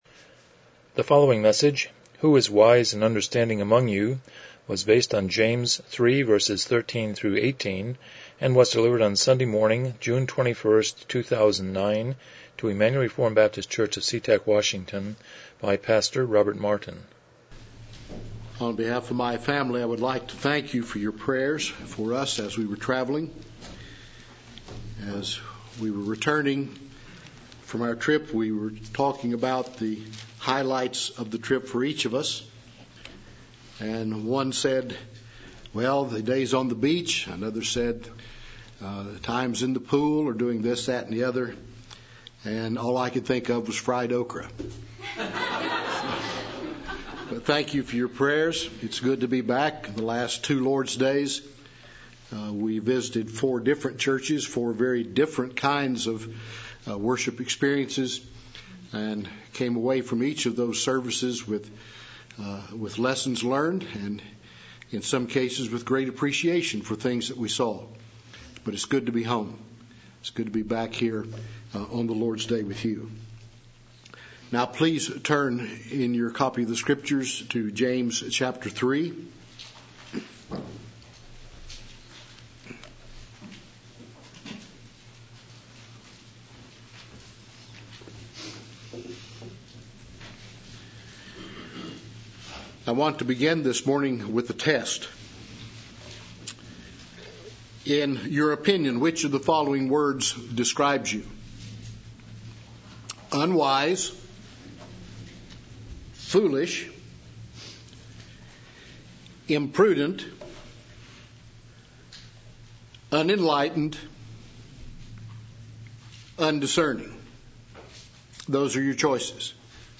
James 3:13-18 Service Type: Morning Worship « 35 What is a Covenant?